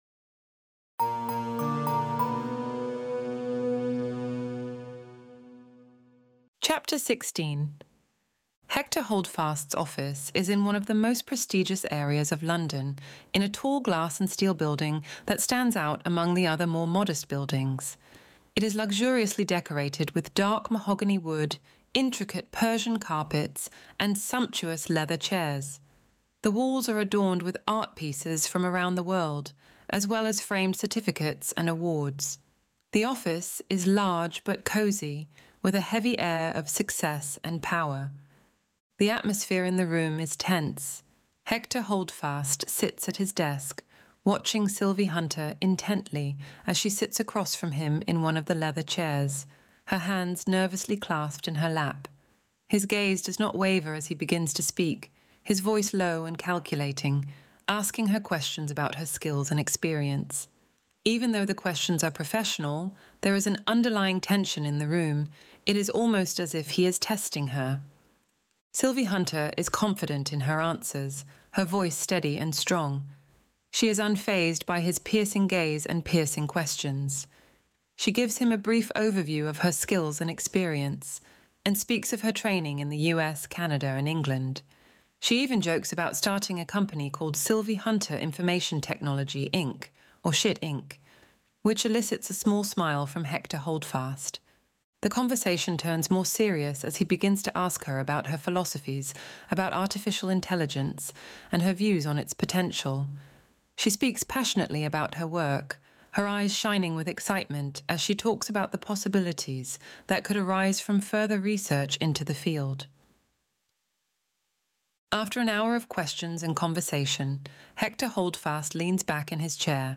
Extinction Event Audiobook Chapter 16